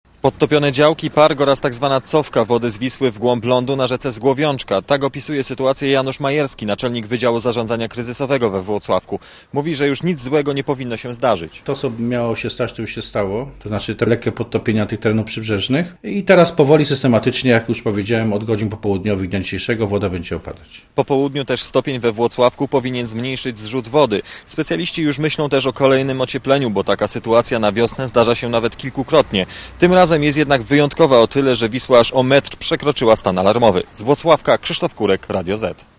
Podobnie jest we Włocławku, gdzie jest reporter Radia ZET.